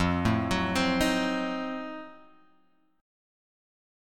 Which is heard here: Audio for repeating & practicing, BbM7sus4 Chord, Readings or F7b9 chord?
F7b9 chord